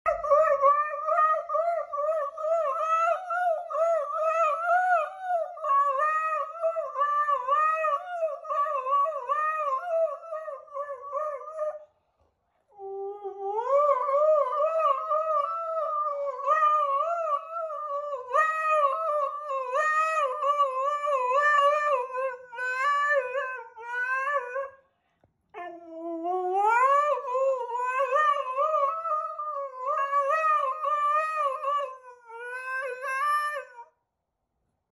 Singing His Way To Turkey Sound Effects Free Download